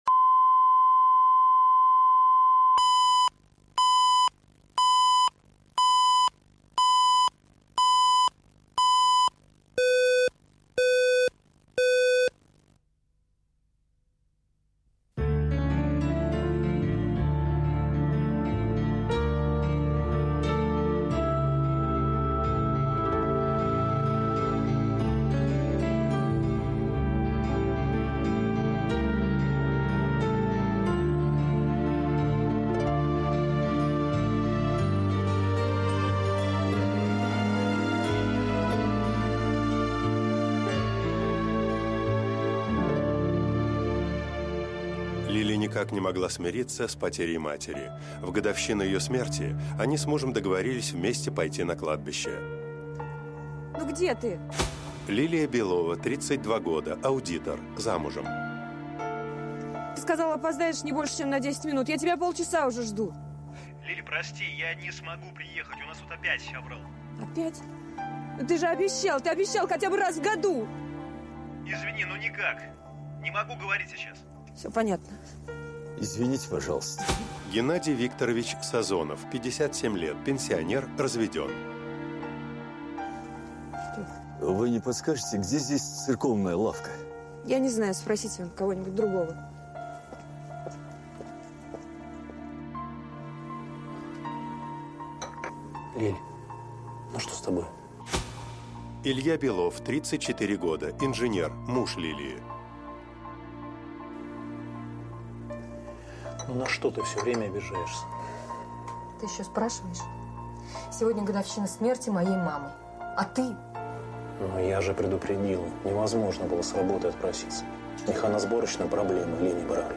Аудиокнига Прошлое не отпускает | Библиотека аудиокниг